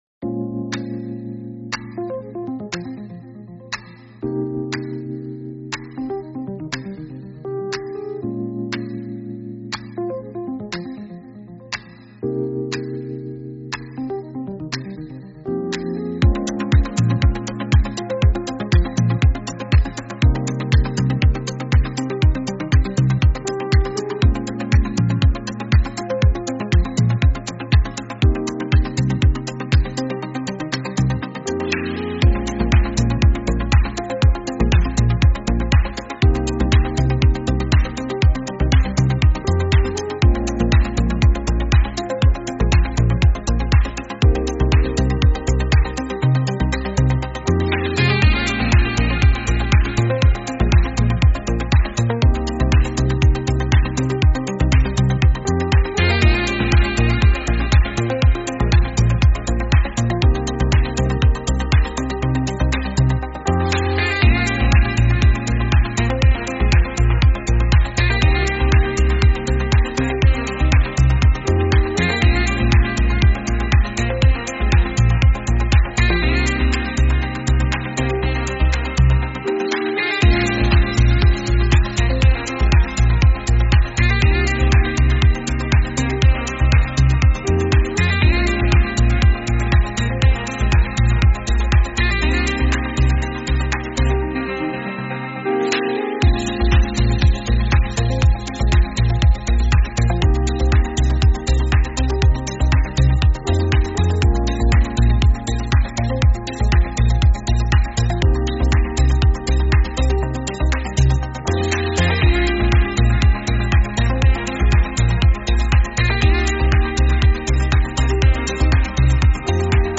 Instrumental -Real Liberty Media DOT xyz